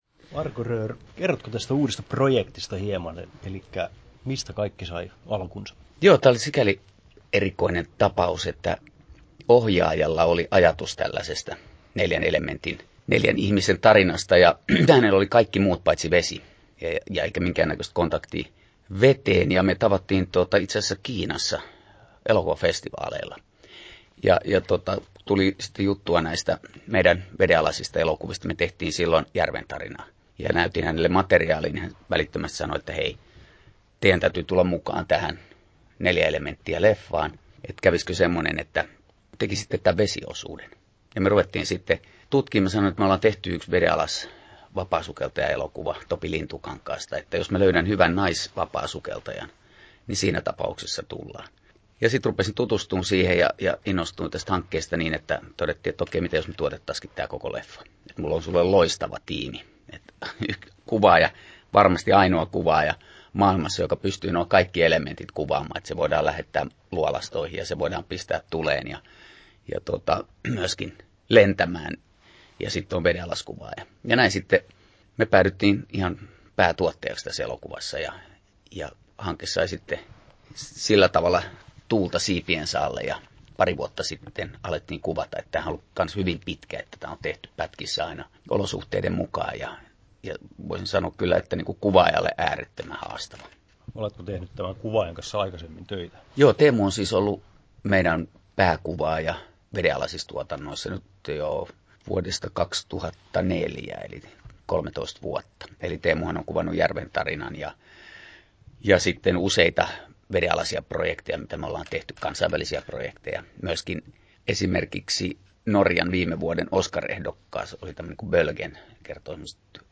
Haastattelussa
10'53" Tallennettu: 08.03.2017, Turku Toimittaja